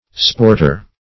Search Result for " sporter" : The Collaborative International Dictionary of English v.0.48: Sporter \Sport"er\ (-[~e]r), n. One who sports; a sportsman.